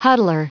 Prononciation du mot huddler en anglais (fichier audio)
Prononciation du mot : huddler